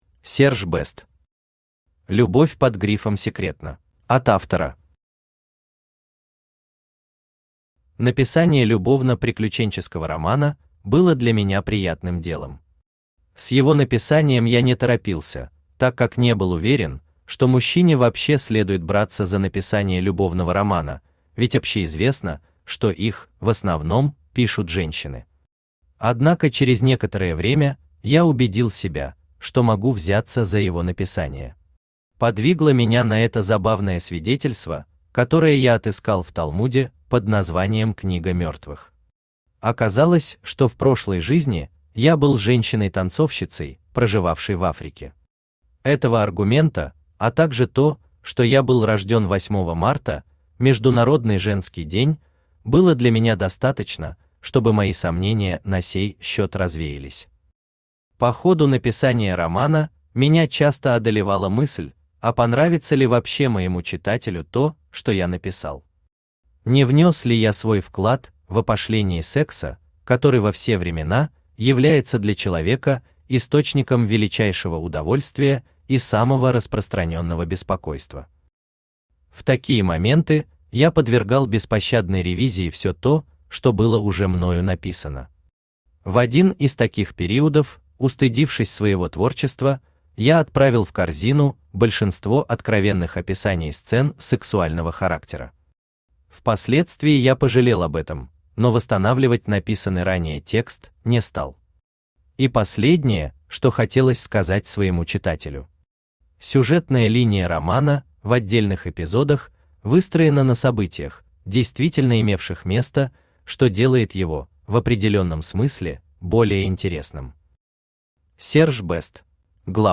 Aудиокнига Любовь под грифом «секретно» Автор Серж Бэст Читает аудиокнигу Авточтец ЛитРес.